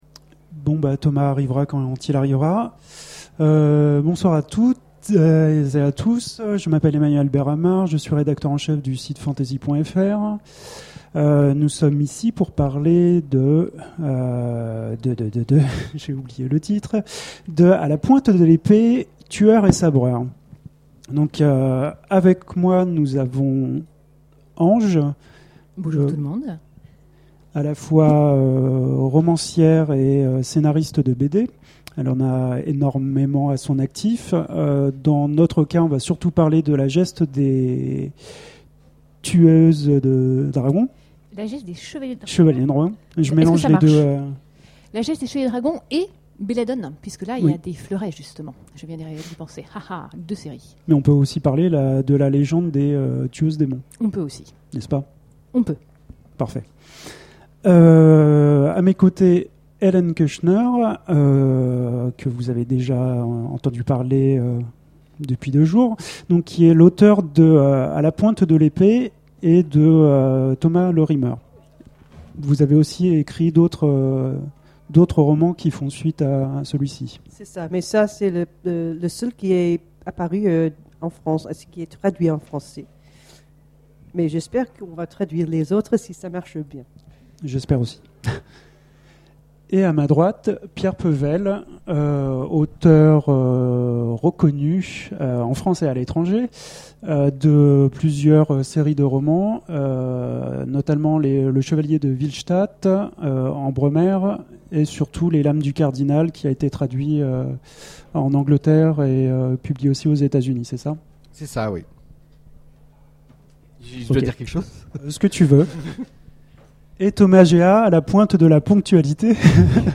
Imaginales 2011 : Conférence A la pointe de l'épée
Voici l'enregistrement de la conférence "A la pointe de l'épée"...